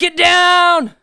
SCI-GRENADE1.WAV